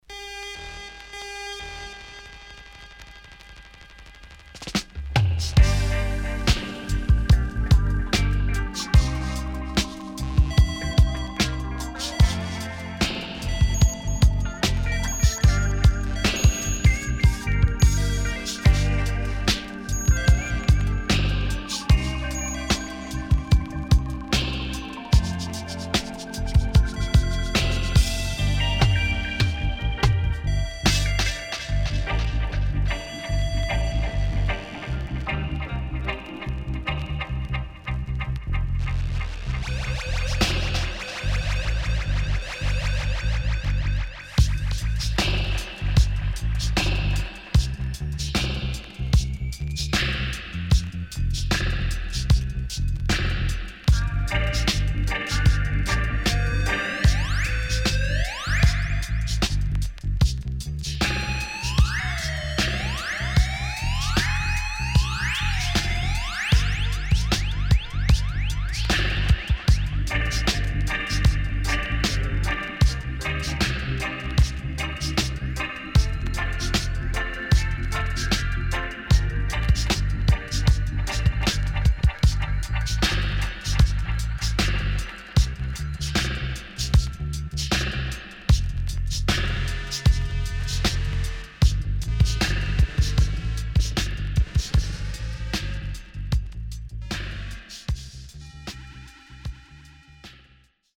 HOME > DUB
SIDE A:所々チリノイズ入ります。